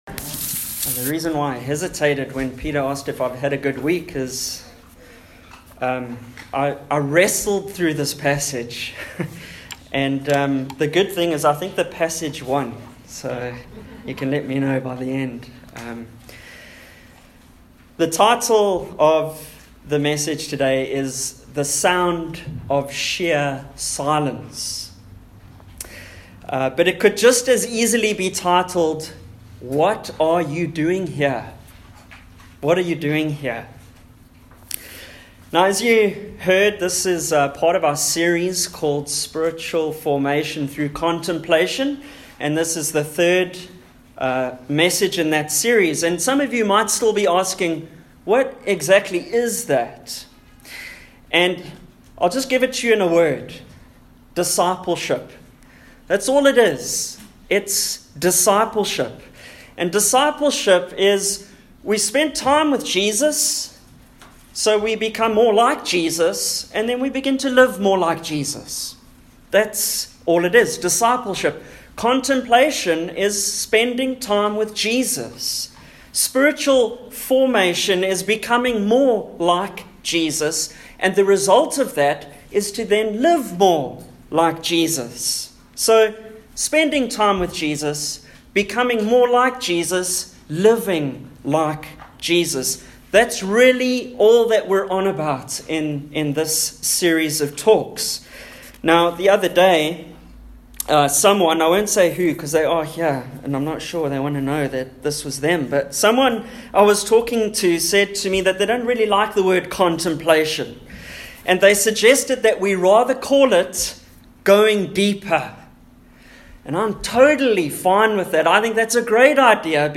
1 Kings 19 Service Type: Sunday AM Topics: Elijah , silence , solitude « Why Are We Focusing on Spiritual Formation?